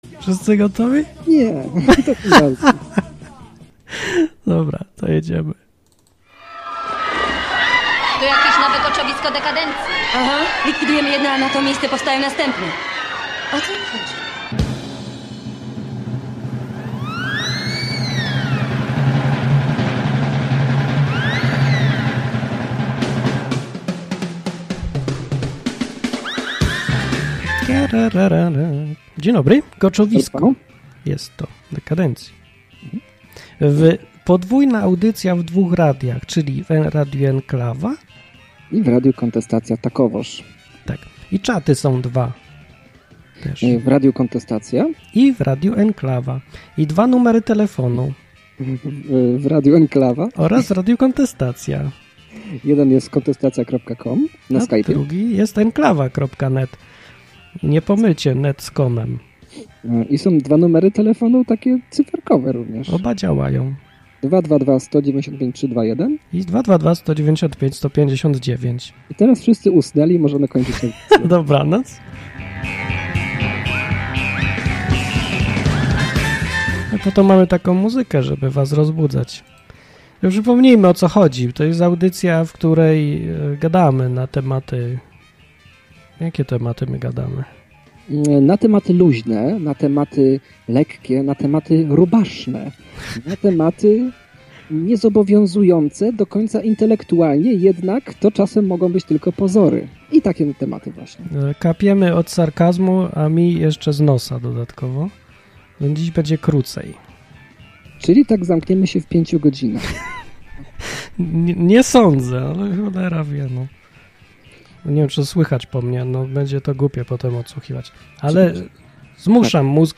Koczowisko Dekandencji to dwu-radiowa audycja, w której od luzu, sarkazmu i ironii wióry lecą.